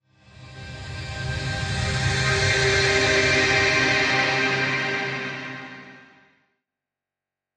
Ambiance atmosphere happy euphoric fantasy